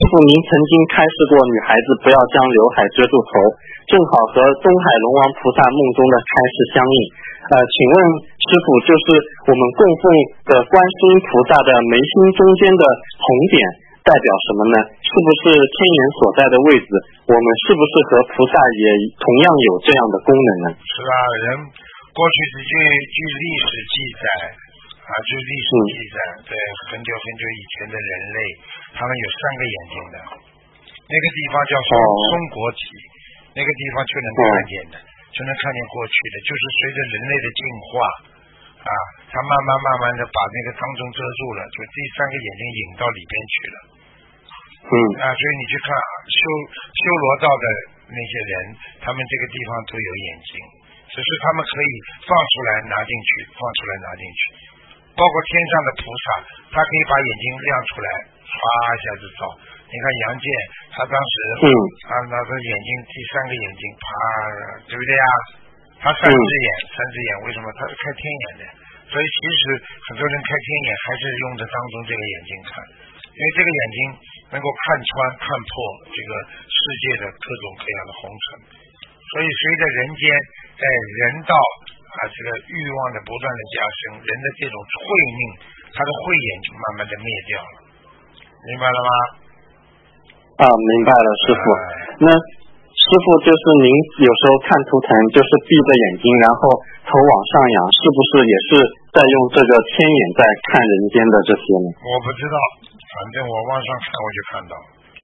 电台录音精选